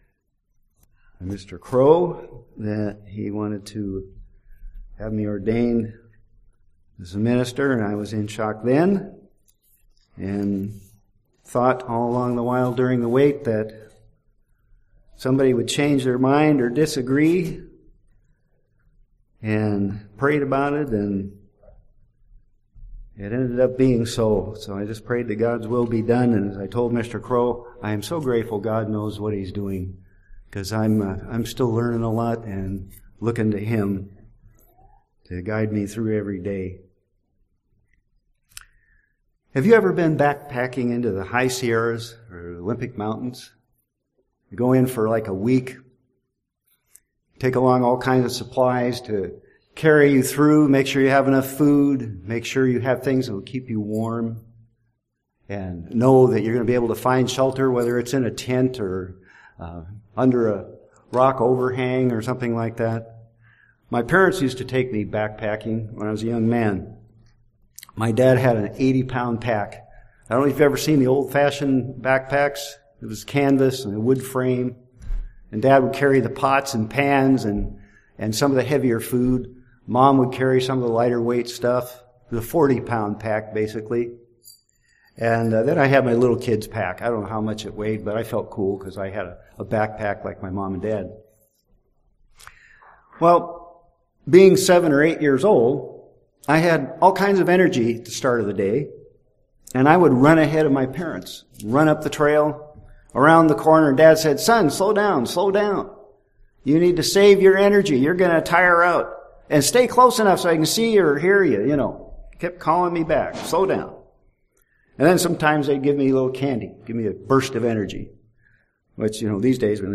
Listen to this sermon to learn why it's so important to wait on God continually, and to keep justice, mercy and faith. There are great rewards in store for those who wait on God continually!